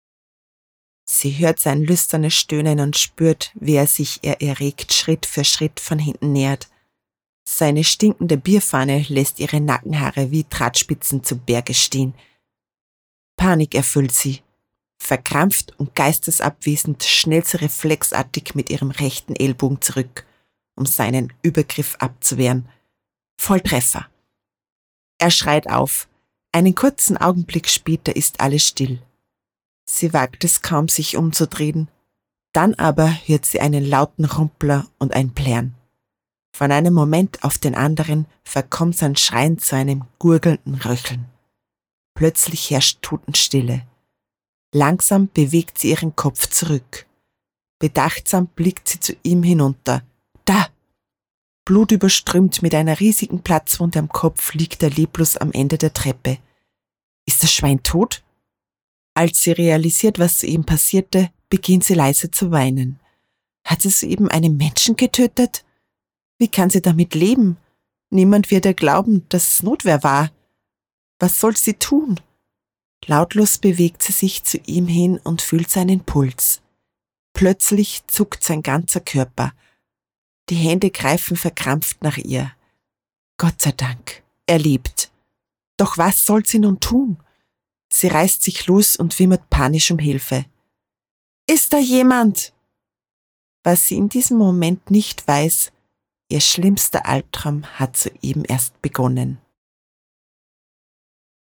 Hörbücher
Kriminalroman
In meinem Studio arbeite ich ausschließlich mit hochwertigem Equipment und garantiere Dir somit hohe Qualität und top Service.